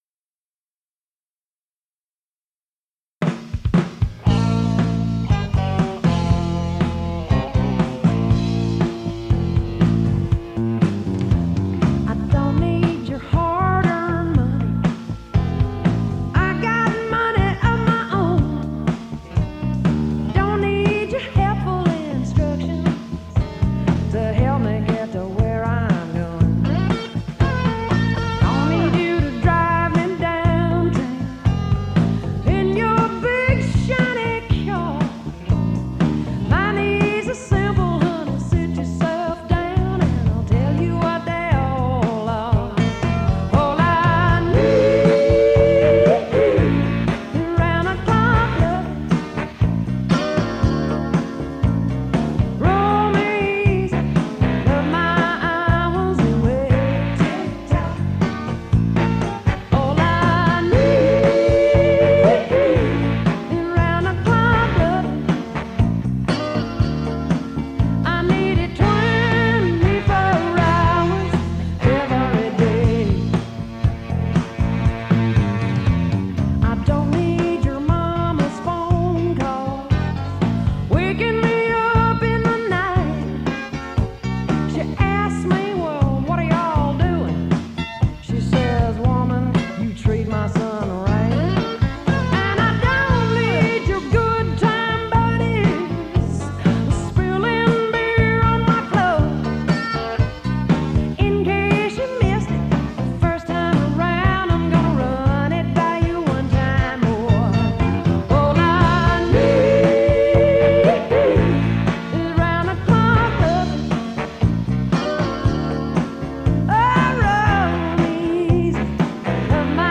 Country music